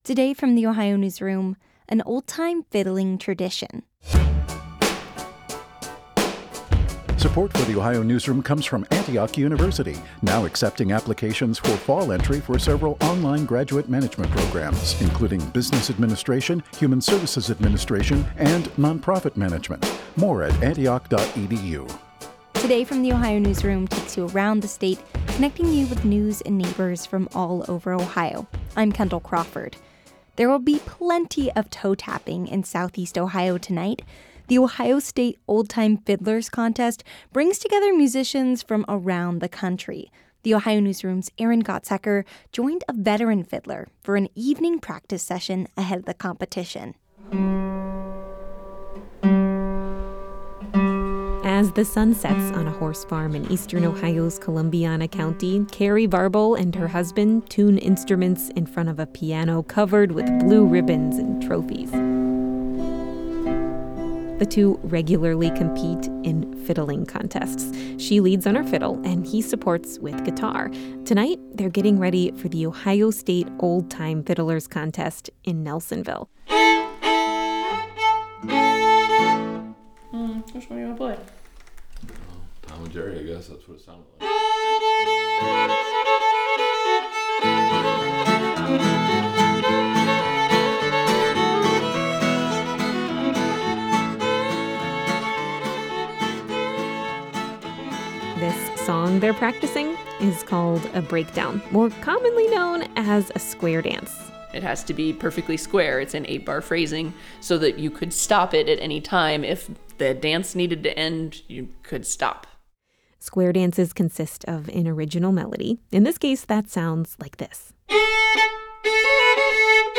fiddling-contest-web.mp3